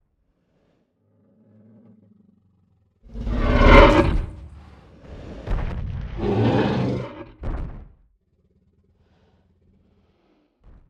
また、ティラノサウルスのように、現代には存在していないものはどうしているのか気になりましたが、ジュラシック・ワールドとのコラボということもあってか (おそらく) 映画で使われている声が含まれています。
ティラノサウルスの鳴き声
Tyrannosaur.mp3